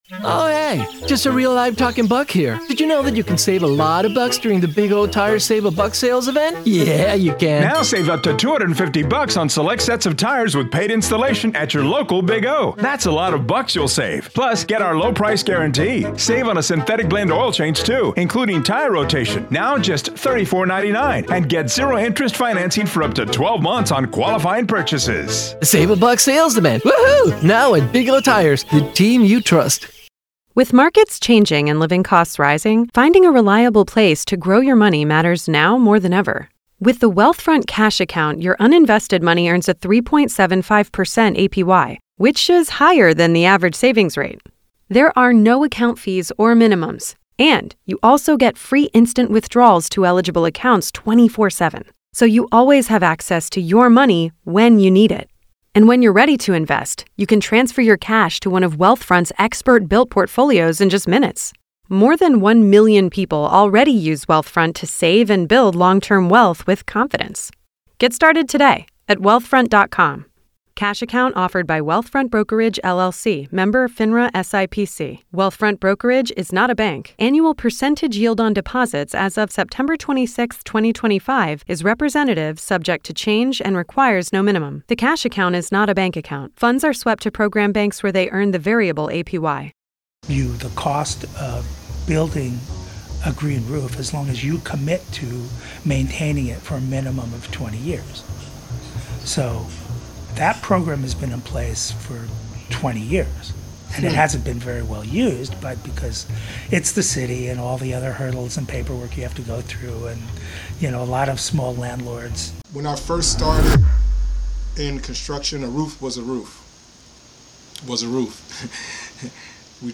This conversation covers the evolution of roofing technology, the challenges consumers face with new sustainable options, and the importance of holistic living using natural resources.